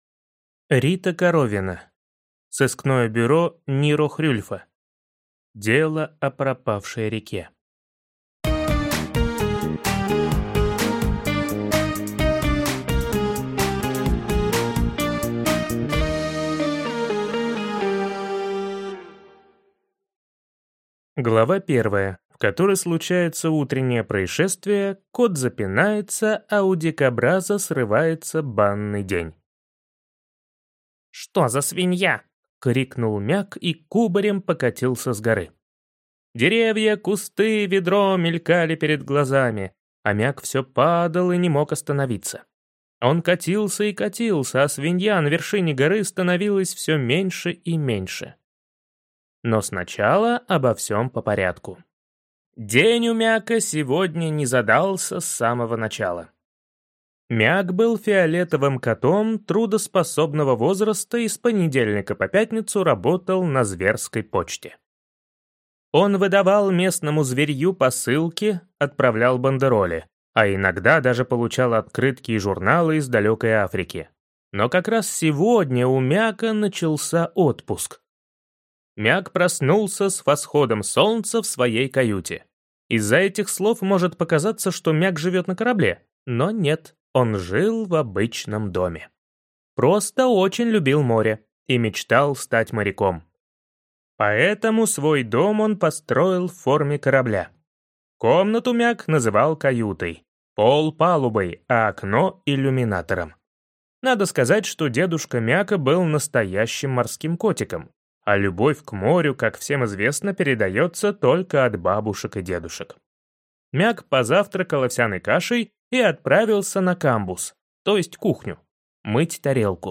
Аудиокнига Сыскное бюро Ниро Хрюльфа. Дело о пропавшей реке | Библиотека аудиокниг